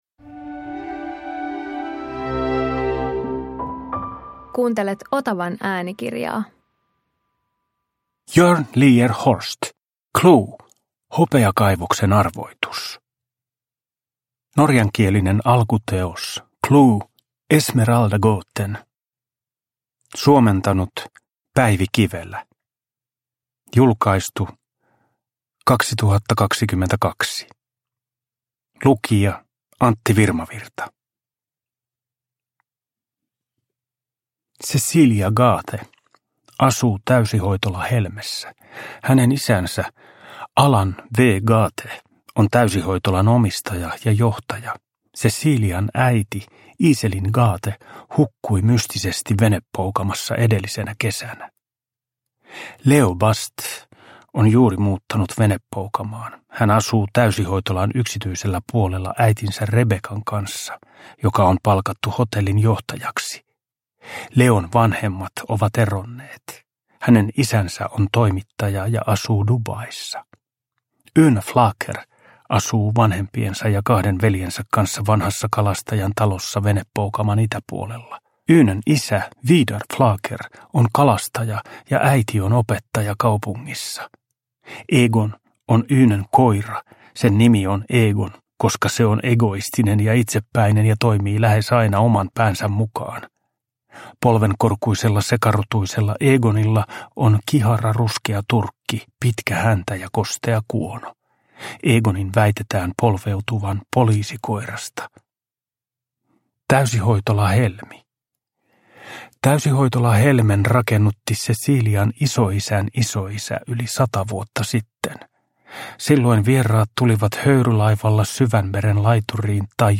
CLUE - Hopeakaivoksen arvoitus – Ljudbok – Laddas ner